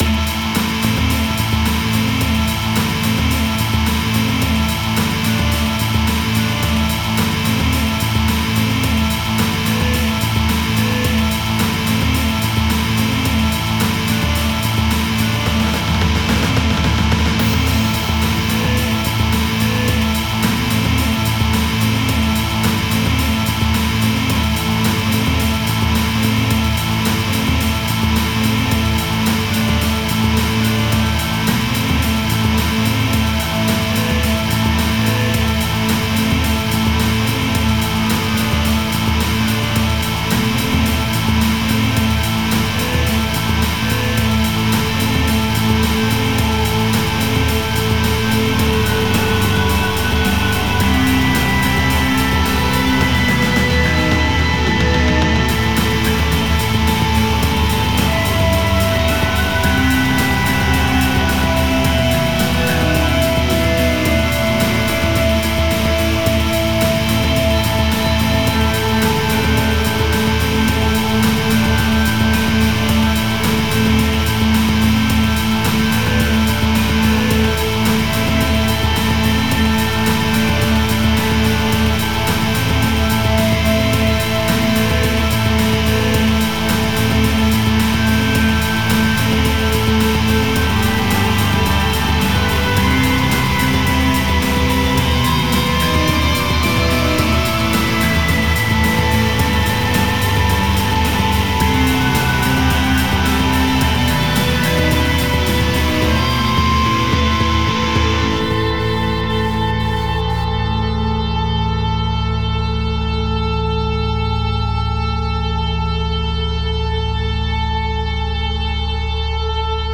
rock instrumental au carrefour du post rock  et de l'indus